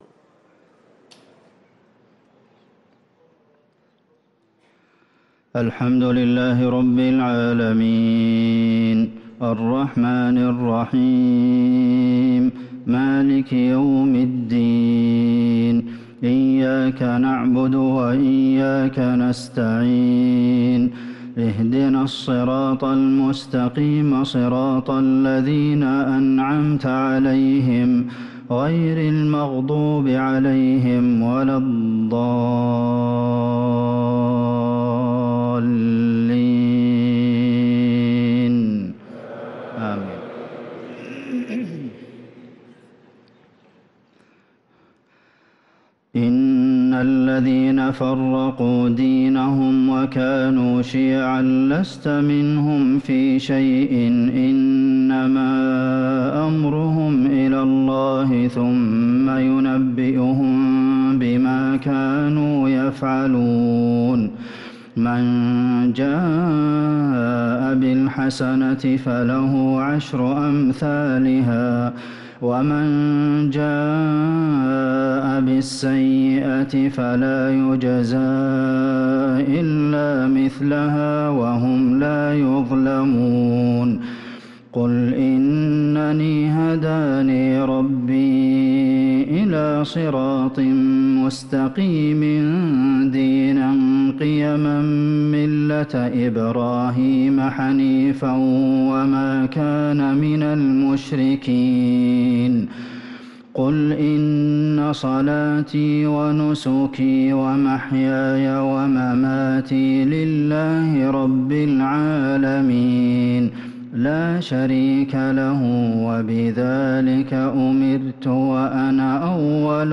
صلاة العشاء للقارئ عبدالمحسن القاسم 13 ربيع الآخر 1445 هـ